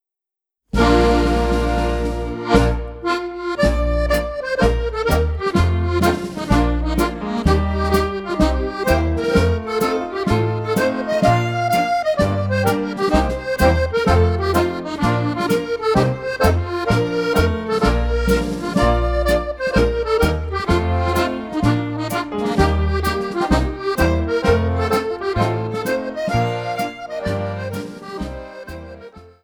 Strathspey